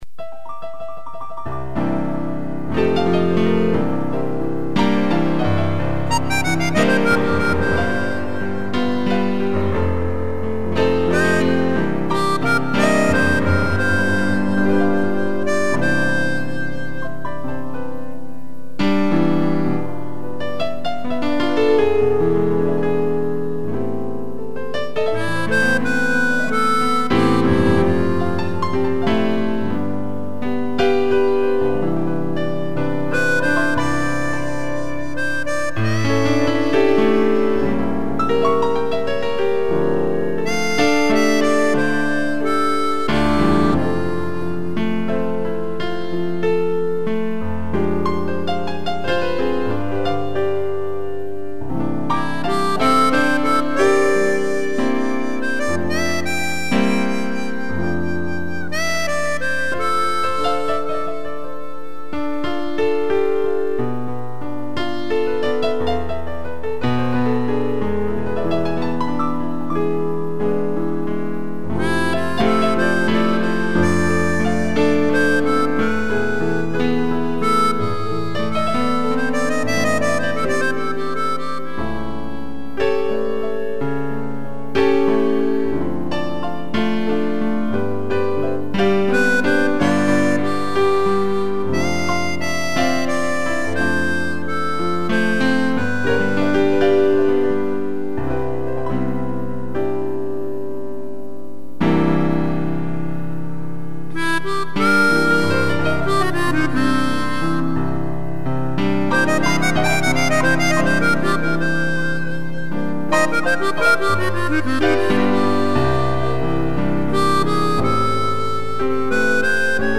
2 pianos e gaita
(instrumental)